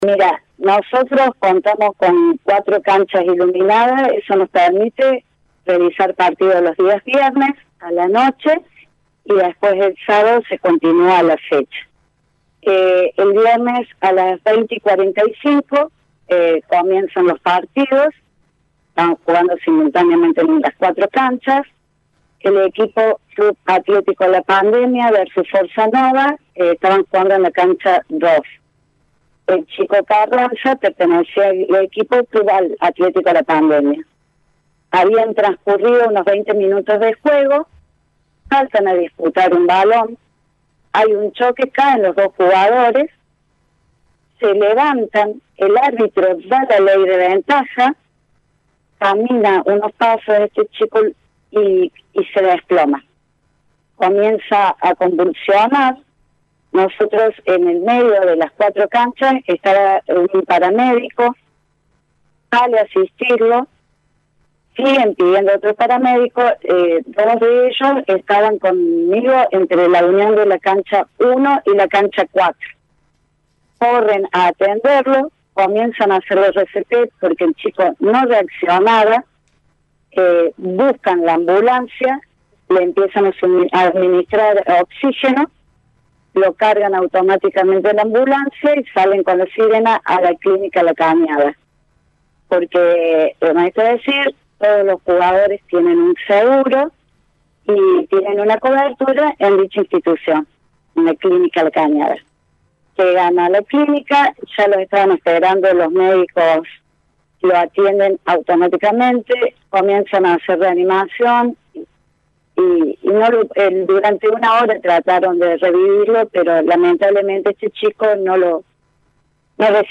habló con Radio Show.